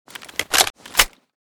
toz34_unjam.ogg